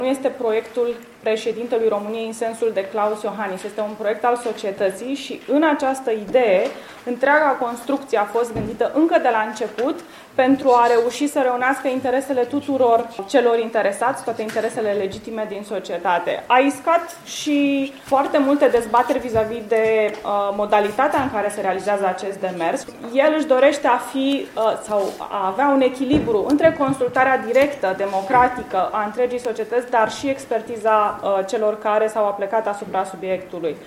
Reprezentantul Președinției României, Ligia Deca: